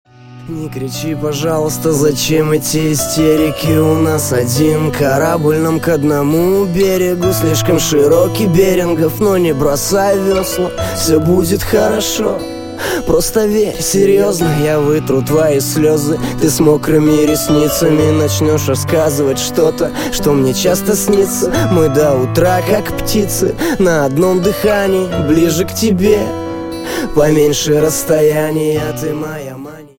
лирика
русский рэп
спокойные
романтичные